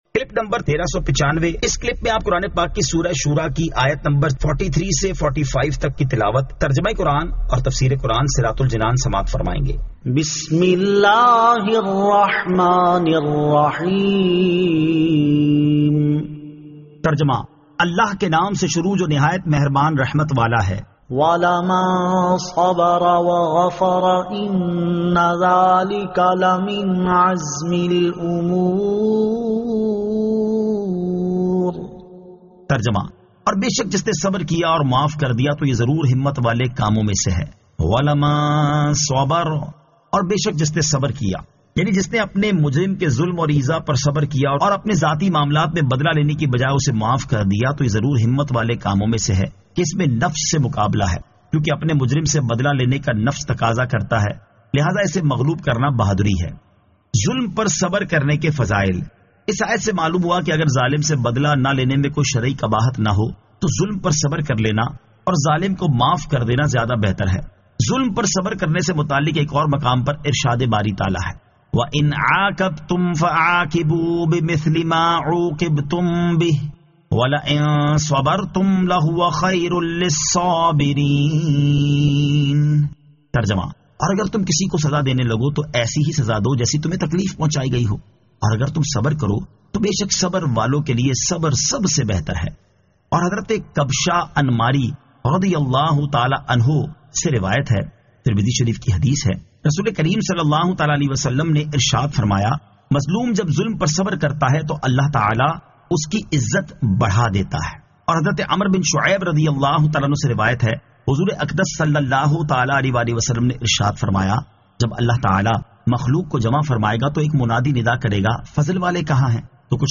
Surah Ash-Shuraa 43 To 45 Tilawat , Tarjama , Tafseer
2023 MP3 MP4 MP4 Share سُوَّرۃُ الشُّوٗرَیٰ آیت 43 تا 45 تلاوت ، ترجمہ ، تفسیر ۔